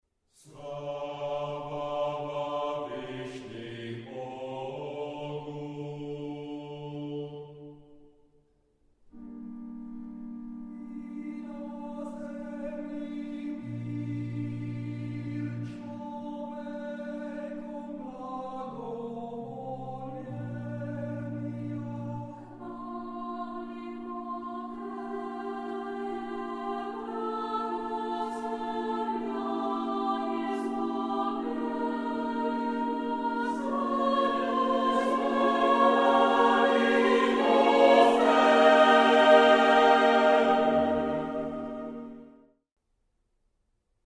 varhany